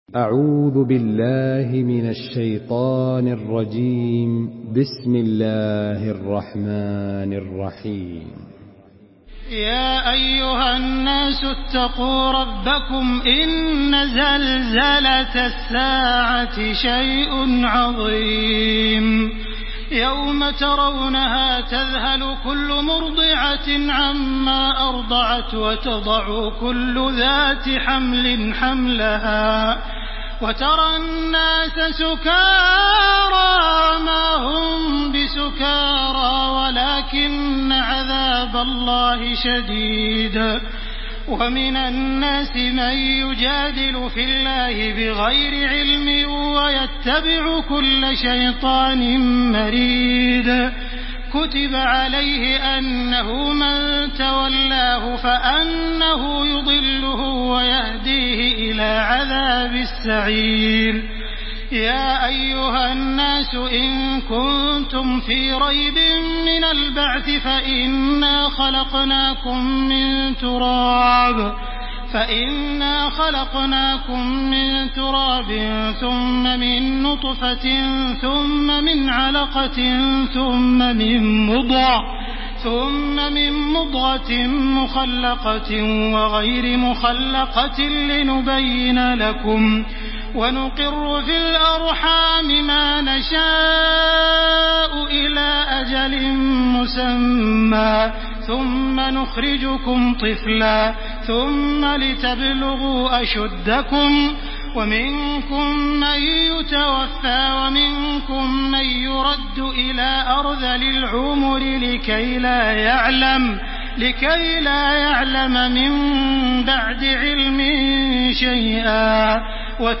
تحميل سورة الحج بصوت تراويح الحرم المكي 1429
مرتل حفص عن عاصم